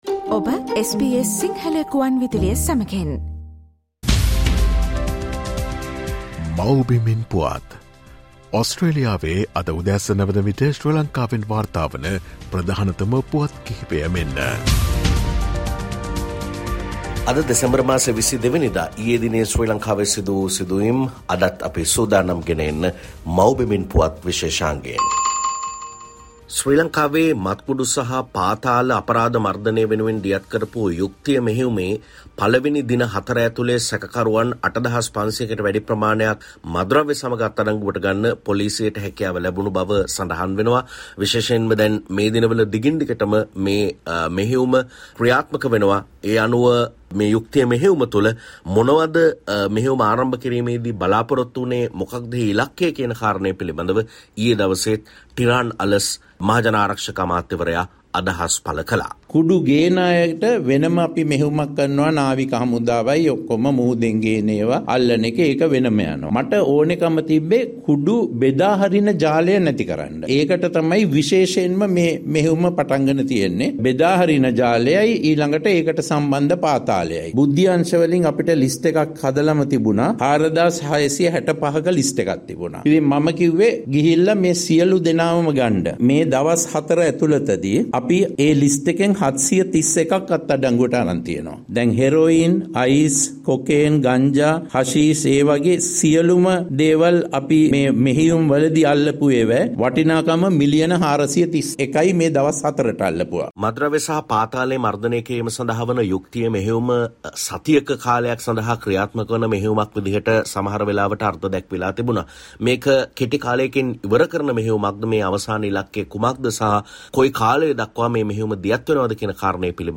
Here are the most prominent News Highlights of Sri Lanka.